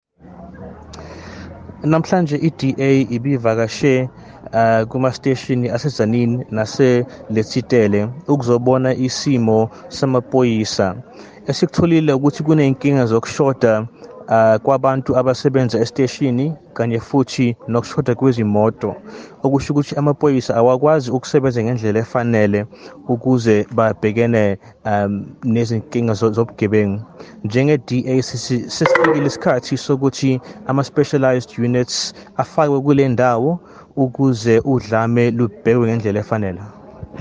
Issued by Zakhele Mbhele MP – DA Shadow Minister of Police
Please find attached soundbites in